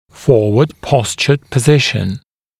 [‘fɔːwəd ‘pɔsʧəd pə’zɪʃ(ə)n][‘фо:уэд ‘посчэд пэ’зиш(э)н]положение со смещением вперед (о нижн. челюсти)